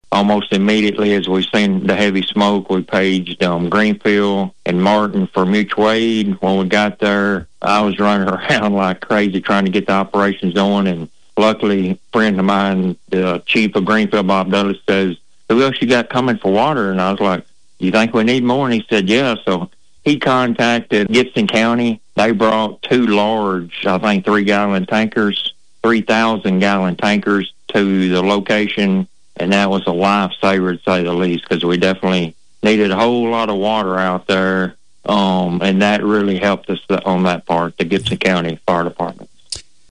Police Chief Gary Eddings tells Thunderbolt News more….